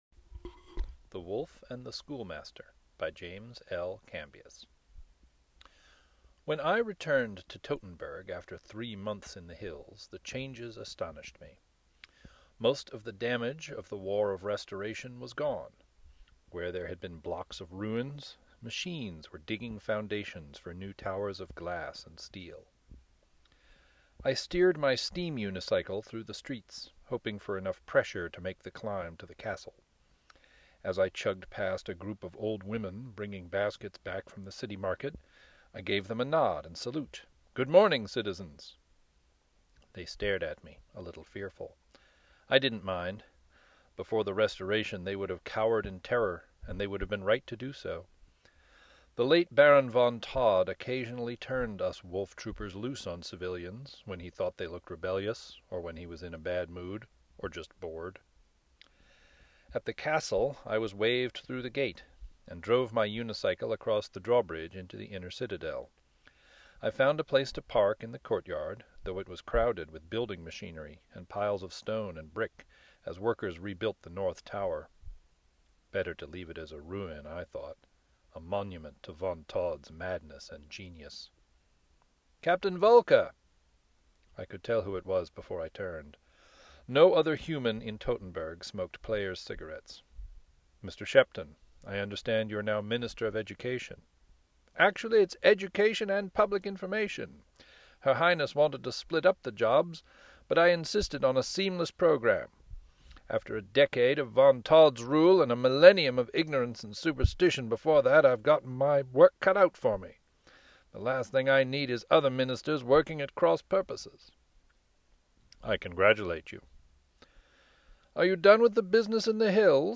Author Spotlight
This is the final installment in our Clockwork Jungle Book author reading series.